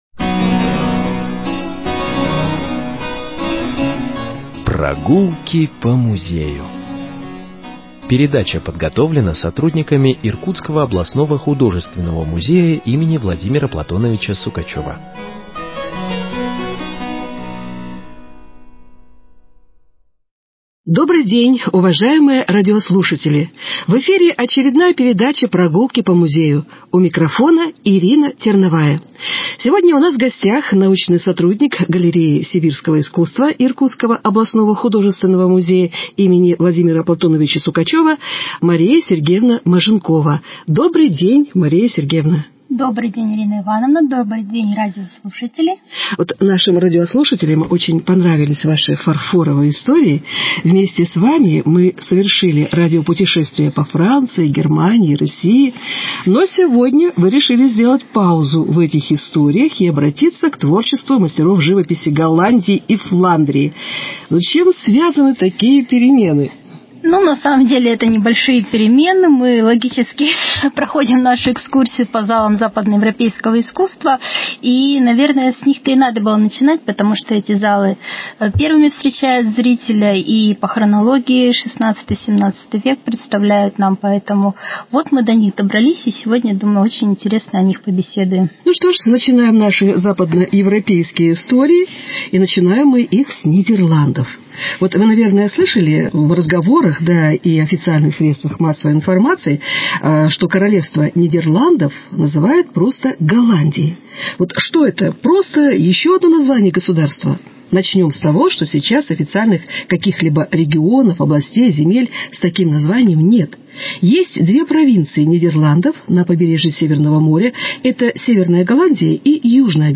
Передача